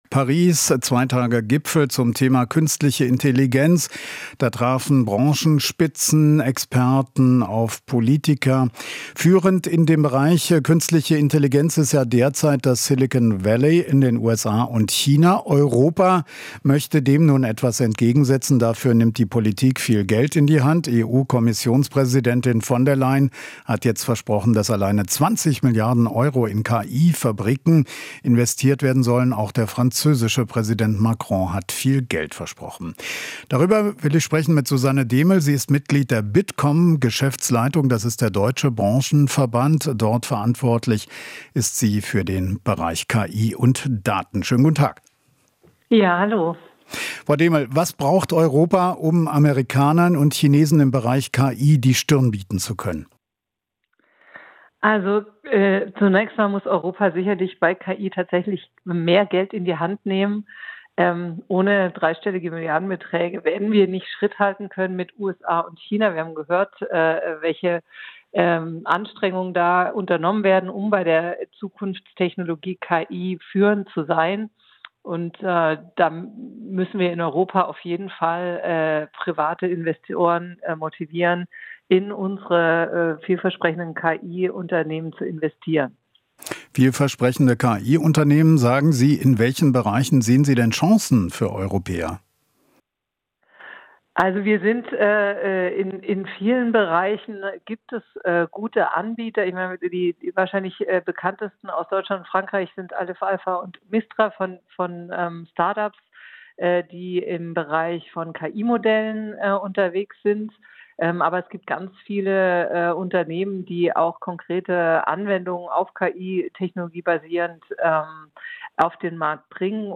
Interview - Bitkom: Milliarden-Investitionen in KI nötig